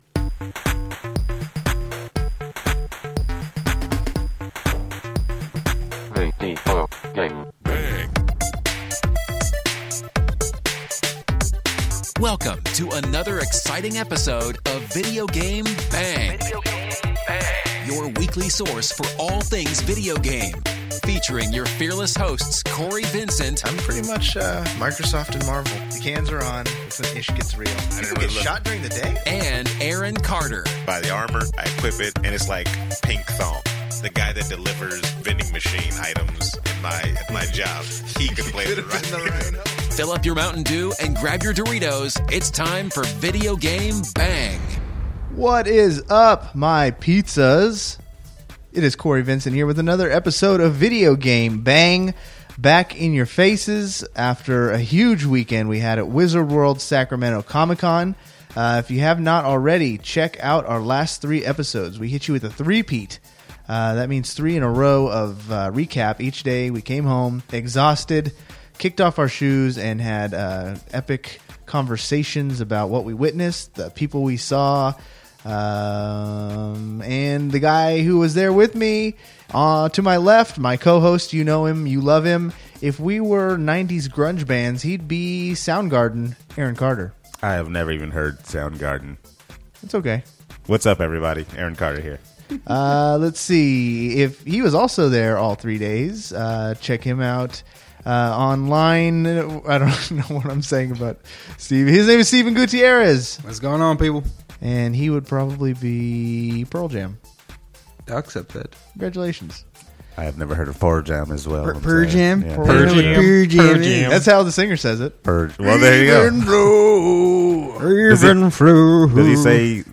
We just kinda go with the flow this week. We talk about some of the big video game releases of the next week. We also reflect a little more on Wizard World now being a few days removed.